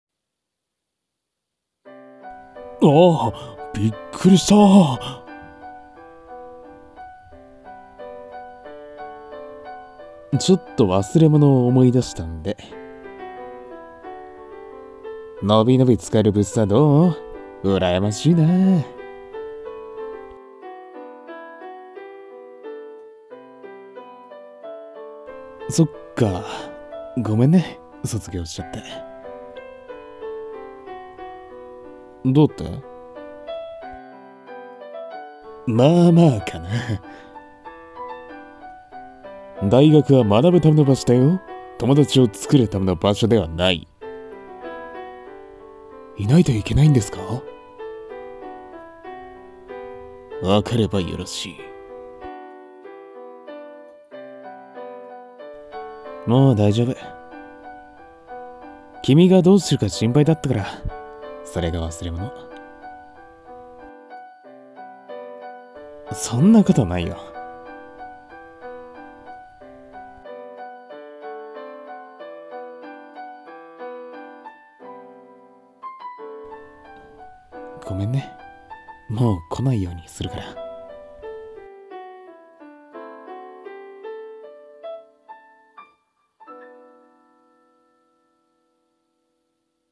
先輩と、部室で。【二人声劇】 演◆募集中